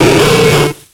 Fichier:Cri 0114 XY.ogg — Poképédia
Cri de Saquedeneu dans Pokémon X et Y.